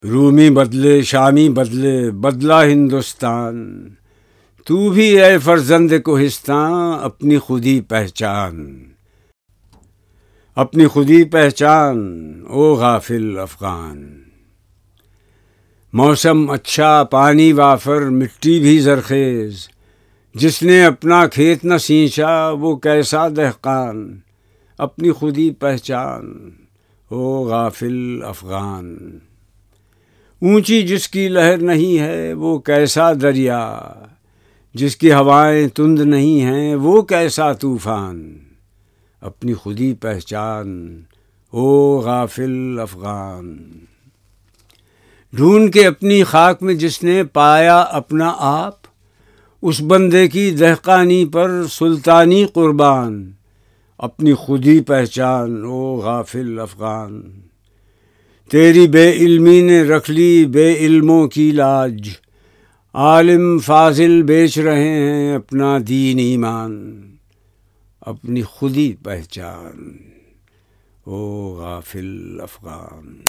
Zia Muhauddin Reads Zarb e Kaleem - International Iqbal Society - Allama Iqbal